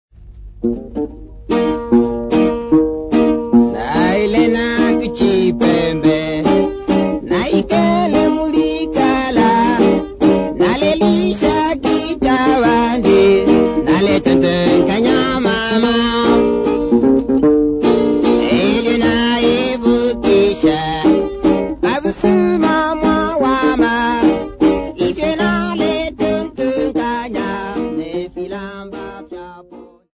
Folk Music
Field recordings
Africa, Sub-Saharan
sound recording-musical
Indigenous music